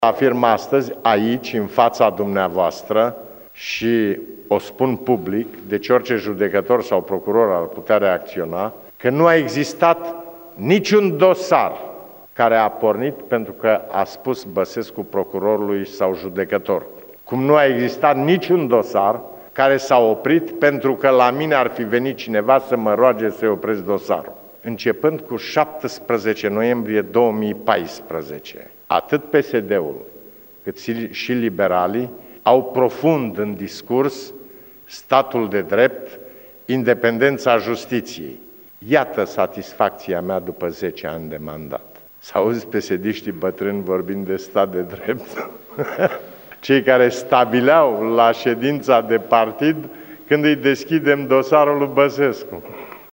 Aşa a afirmat, azi, preşedintele Traian Băsescu, la bilanţul în funcţia de şef al statului.
Progresele din Justiţie s-au făcut pentru ca procurorii şi judecătorii au simţit că sunt liberi, că oamenii politici nu mai sunt intangibili, a spus Băsescu:
basescu-justitie.mp3